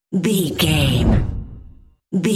Deep whoosh pass by
Sound Effects
dark
tension
whoosh